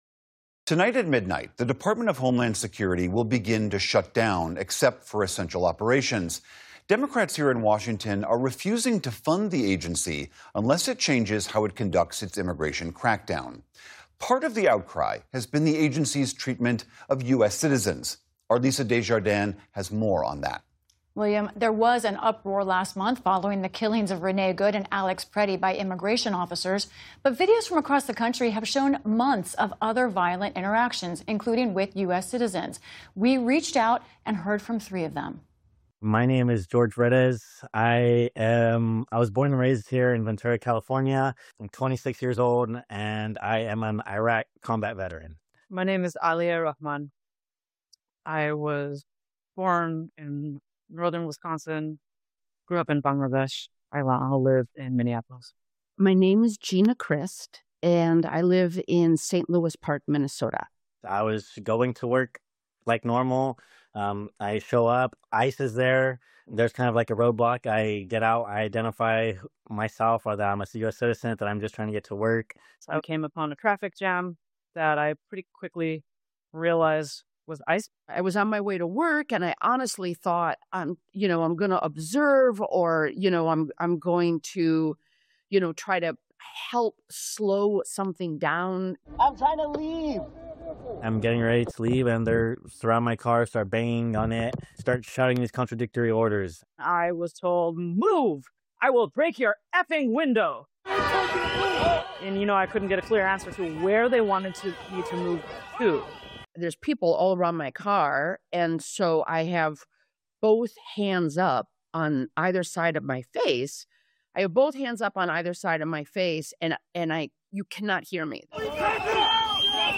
Lisa Desjardins reports, and we hear from some of the people who were detained.